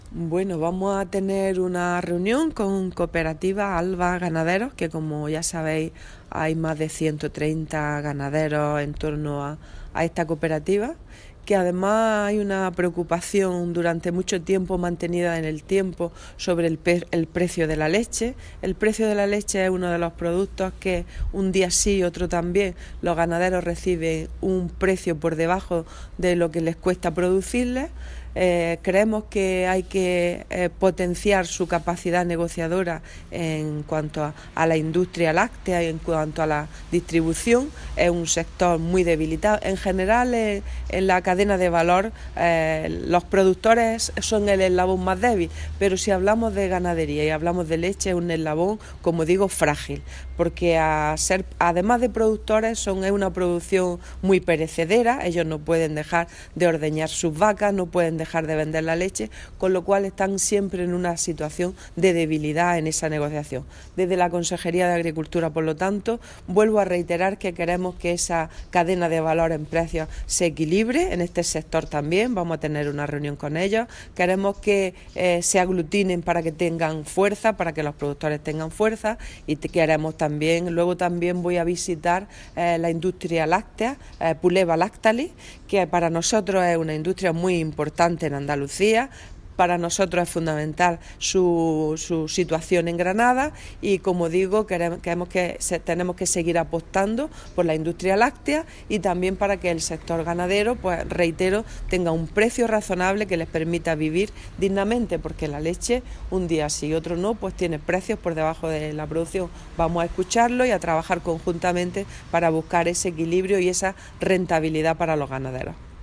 Declaraciones de Carmen Ortiz sobre reunión con Alba Ganaderos y visita a Puleva en Granada